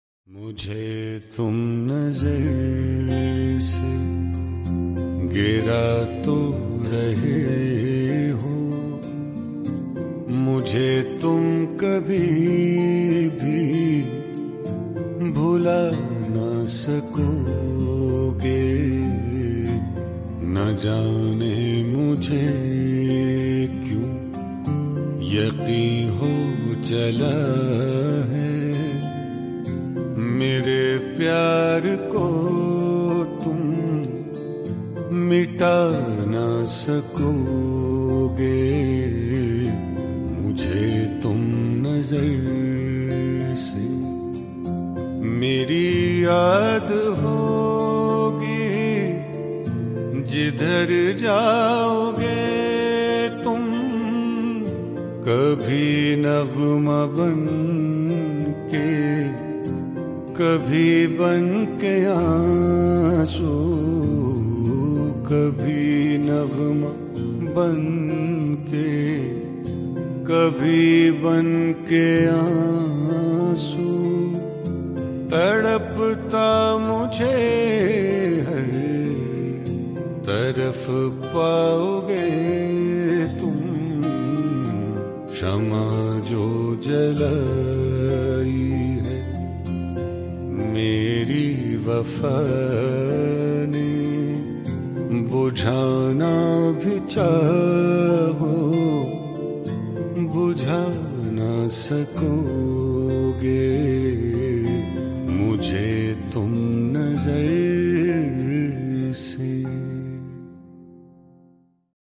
Cover